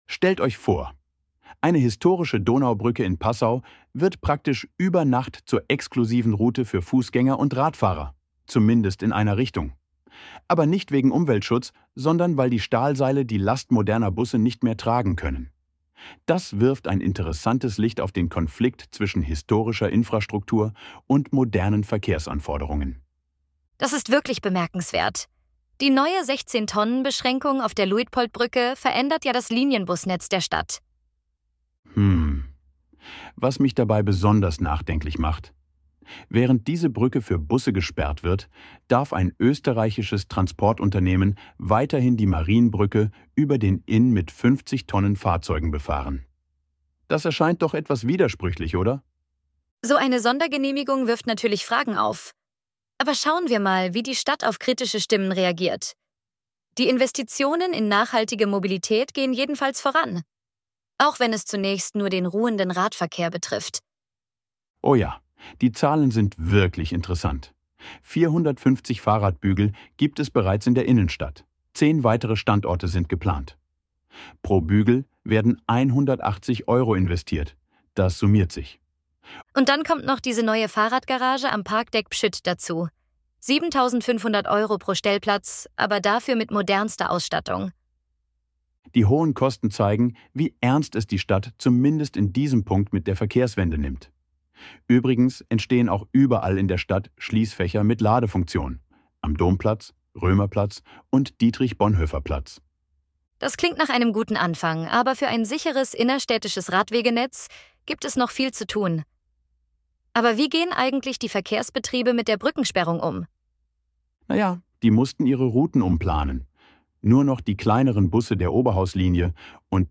Unser Nachrichtenticker vom März als KI-Kost: „Dialog aus der Dose“